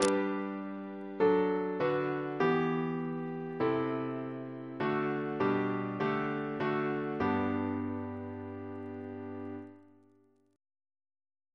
Single chant in G Composer: Edwin George Monk (1819-1900), Organist of York Minster Reference psalters: OCB: 106